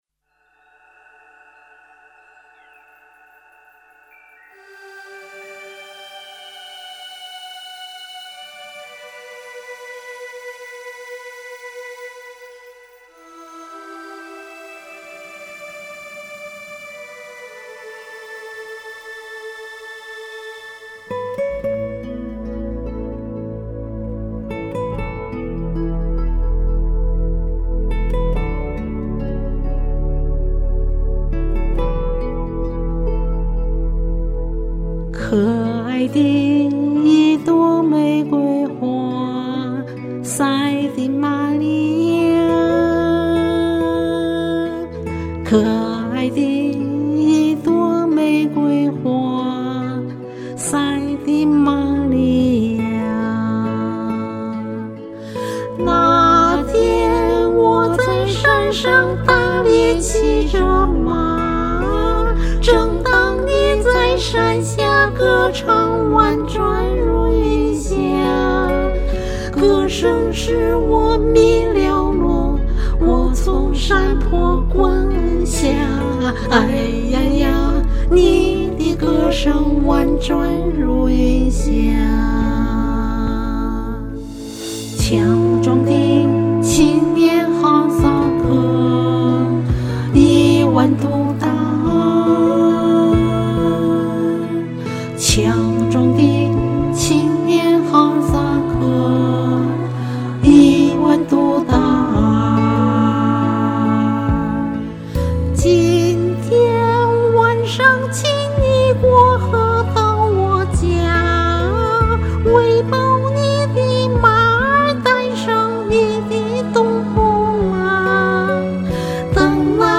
跟个风：也来版和声《可爱的一朵玫瑰花》
《可爱的一朵玫瑰花》（又名《都达尔和玛丽亚》）哈萨克民歌 王洛宾编词曲